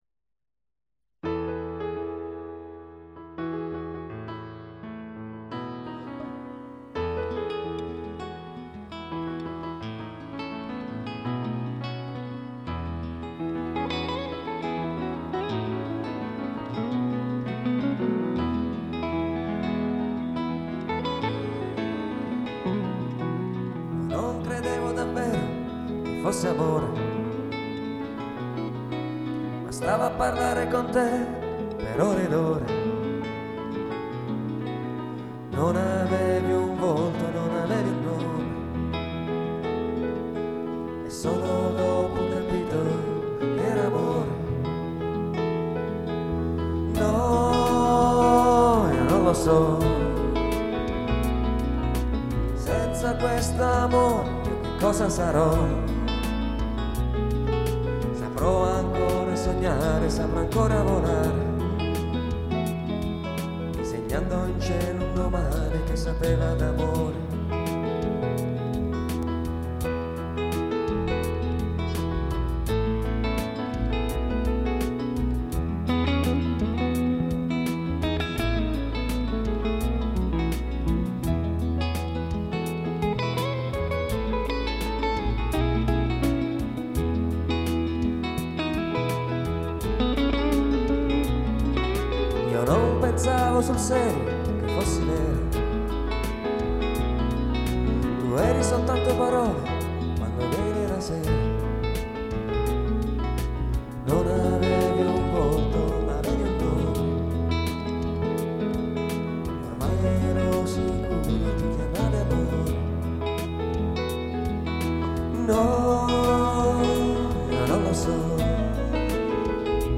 Voce e cori
Piano
Chitarra acustica e elettrica
Basso